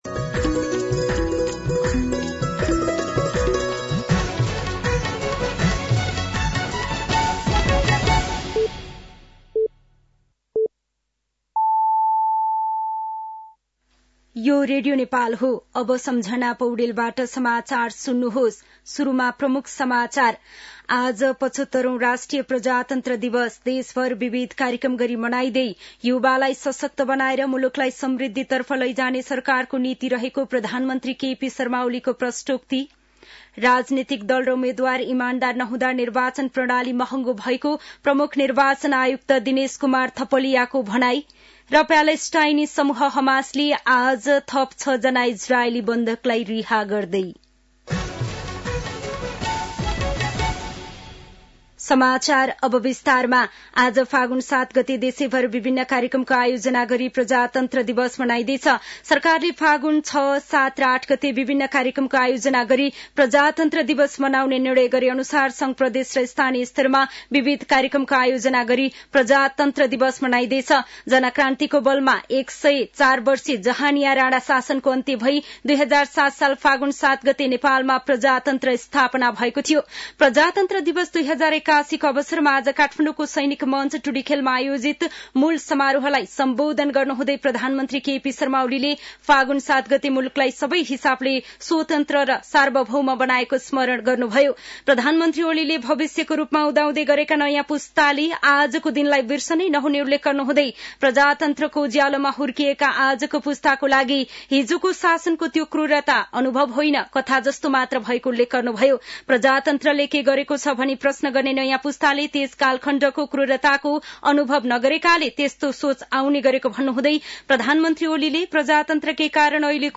दिउँसो ३ बजेको नेपाली समाचार : ८ फागुन , २०८१
3pm-Nepali-News-11-07.mp3